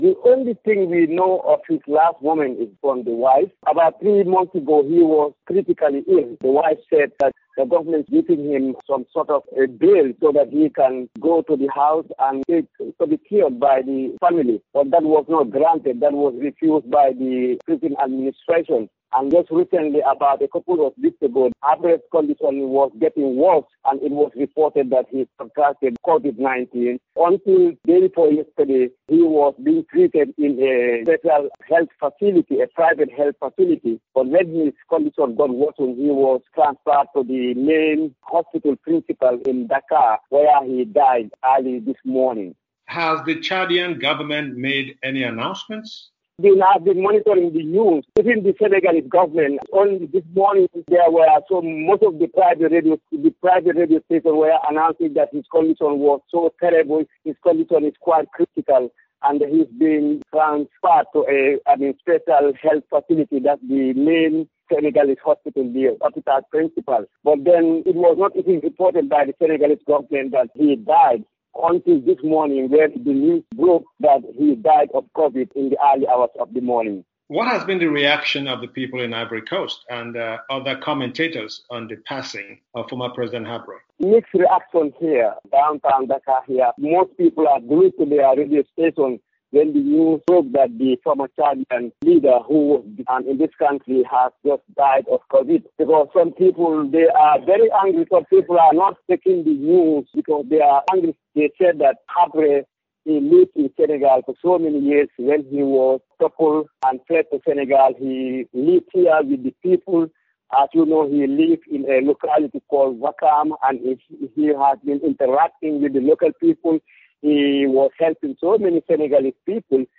Today, we bring the first of three parts of the interview.